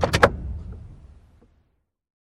CosmicRageSounds / ogg / general / cars / shutdown.ogg
shutdown.ogg